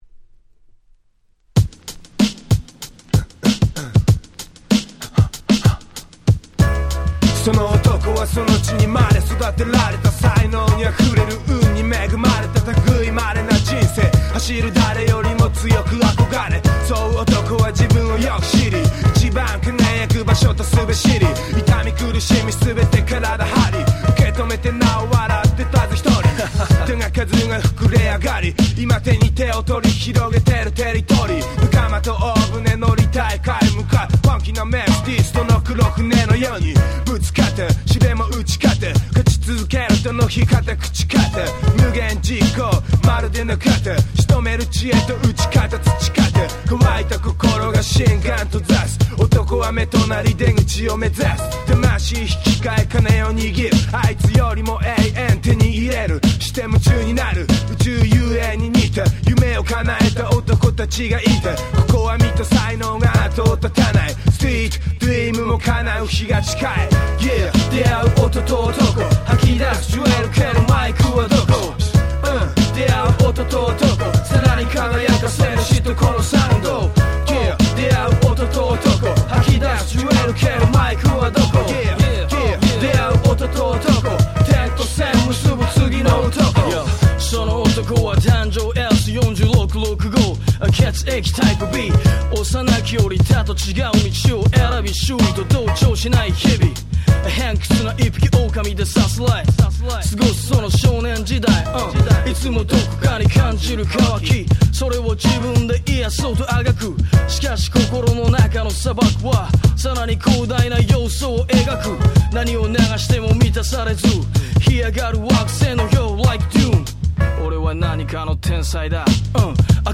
00' Super Nice Japanese Hip Hop !!
日本語ラップ J-Rap